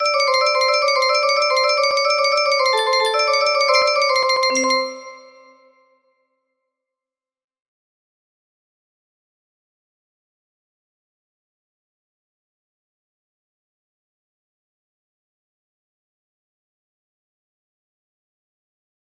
Rescue al nijihanto boj nan HUA HUA KAI TI JOU NO ITAIMANJOU IR! music box melody